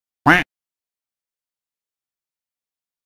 Duck Quack - Sound Effect (HD).mp3